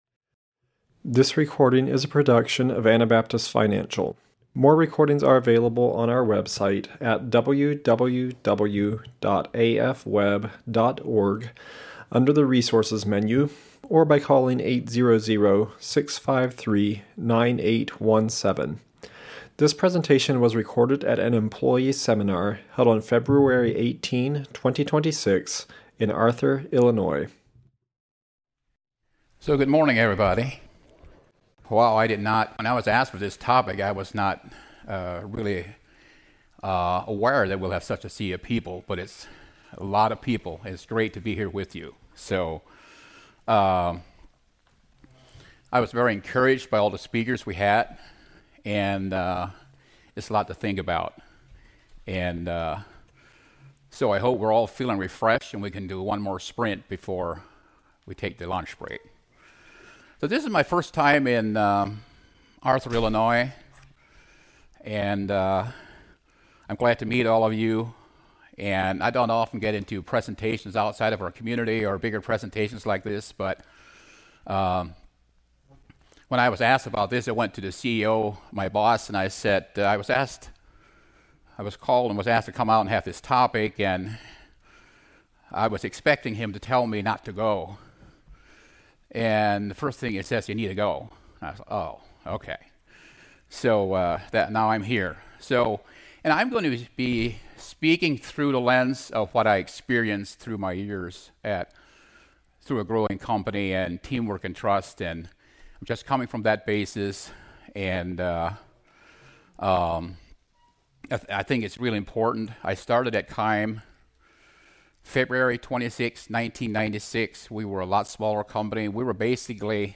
Illinois Employee Seminar 2026